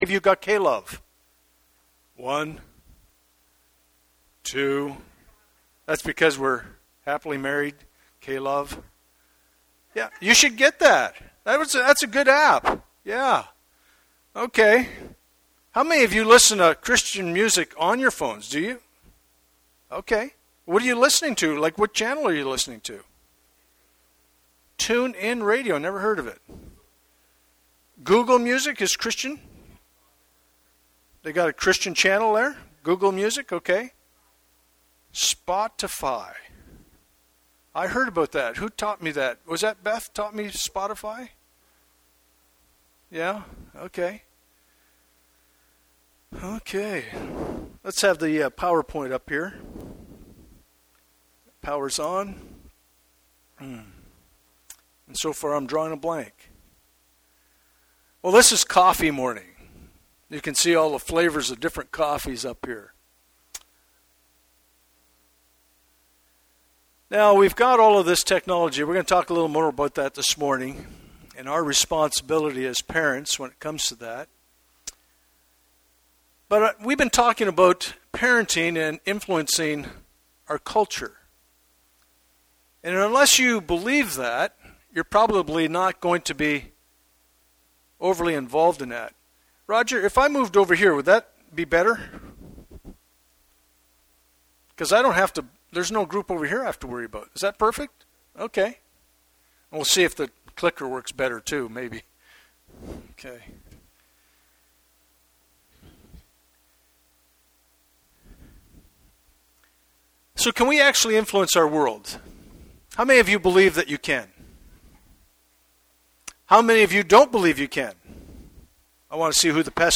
Passage: Proverbs 22:1-12 Service Type: Sunday Morning